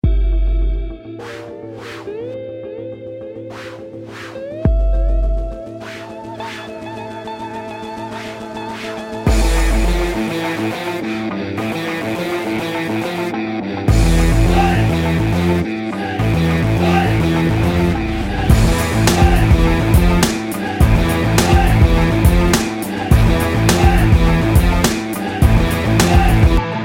громкие
саундтреки
электрогитара
Hard rock
бодрые
Бодрый саундтрек